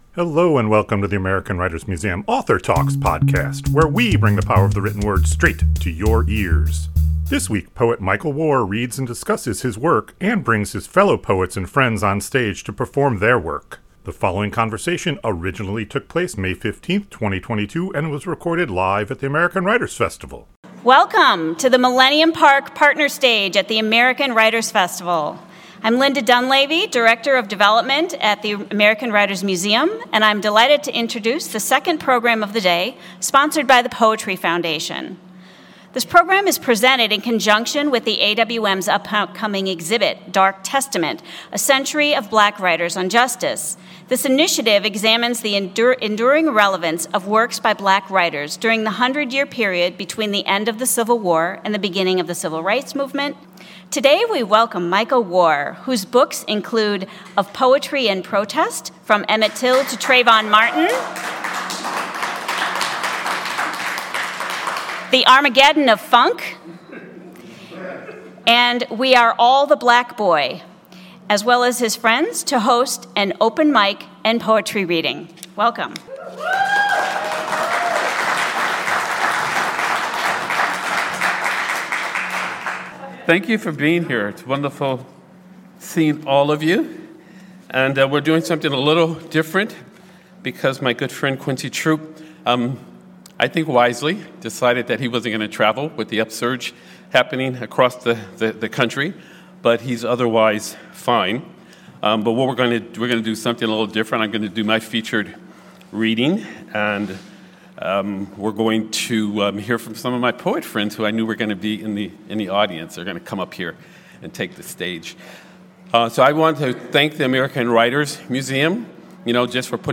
The following conversation originally took place May 15, 2022 and was recorded live at the American Writers Festival.